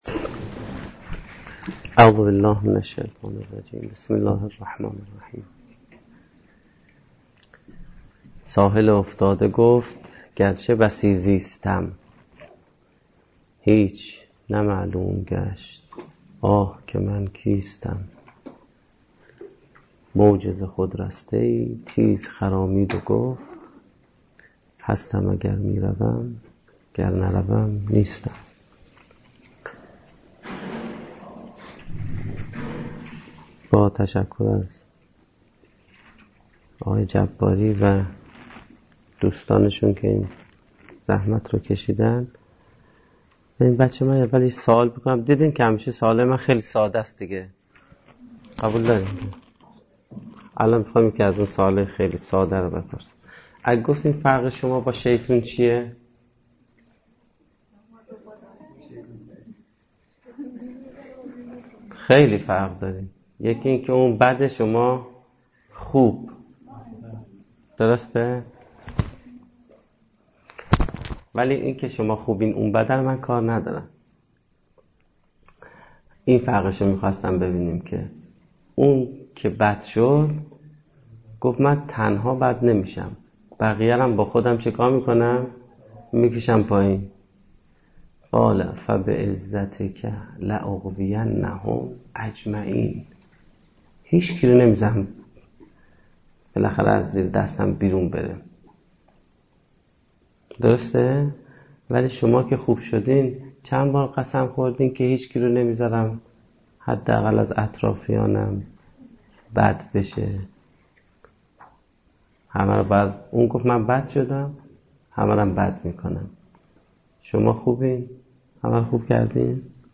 سخنرانی مذهبی